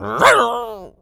pgs/Assets/Audio/Animal_Impersonations/wolf_bark_01.wav at master
wolf_bark_01.wav